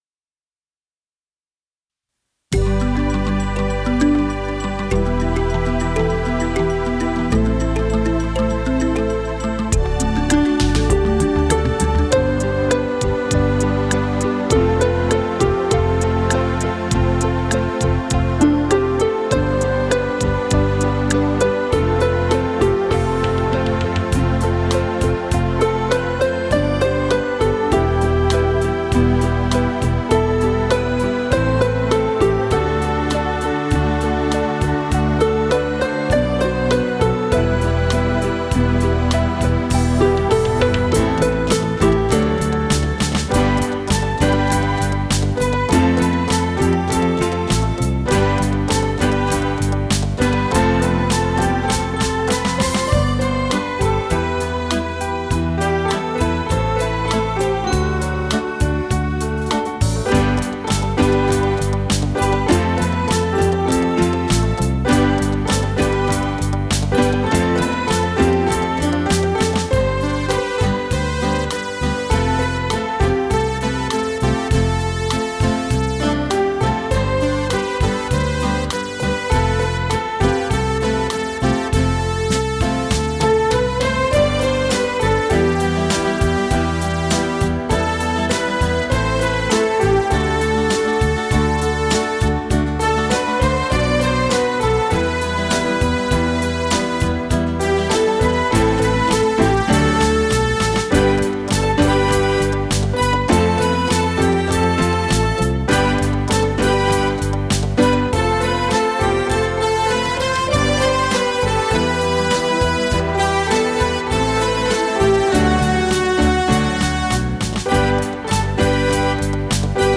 restored legacy synthesizer